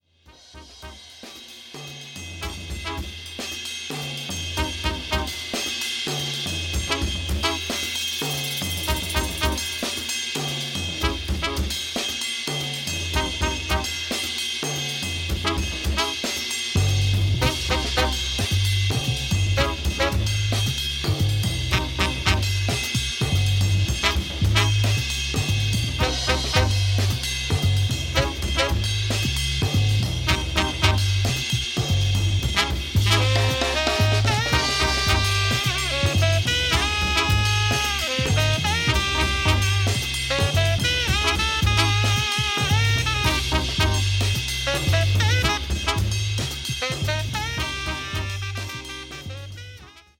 Jazz Fusion LP